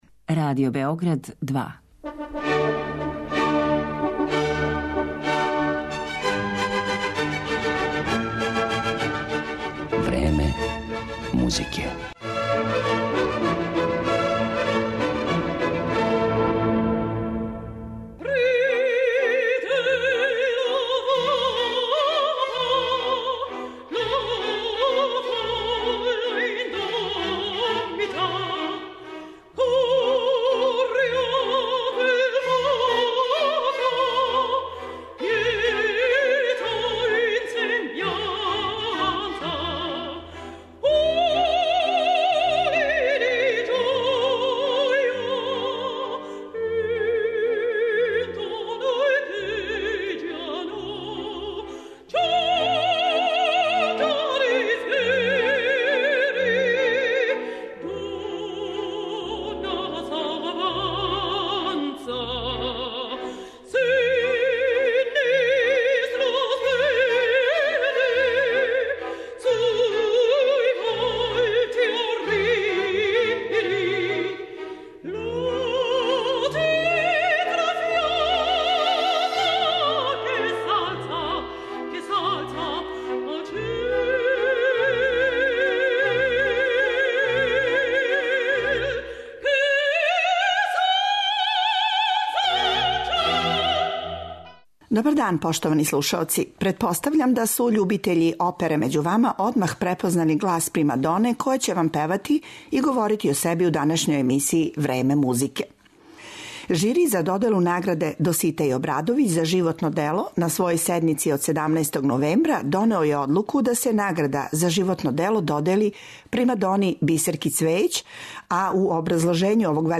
Такође, у емисији ће бити емитовани и снимци неких од њених омиљених улога из опера Маснеа, Бизеа, Сен Санса и Вердија.